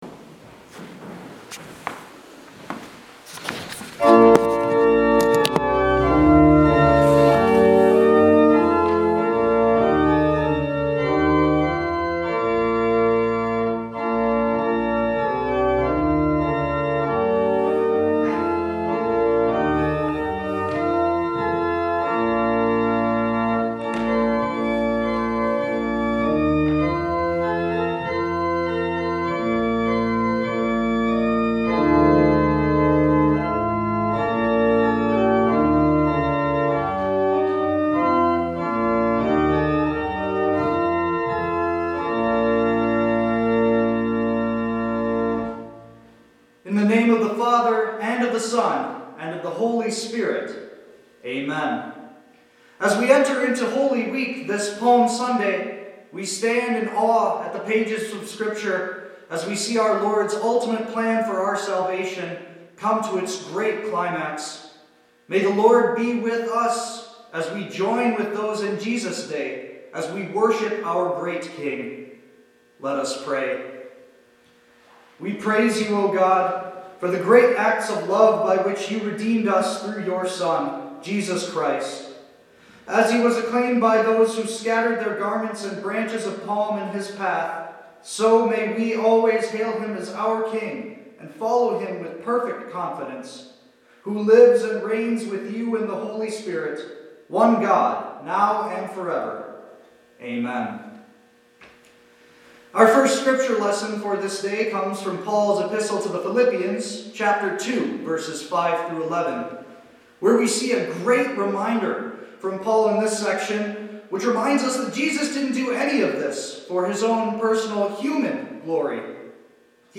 Christ is Coming – April 5th, 2020 Full Service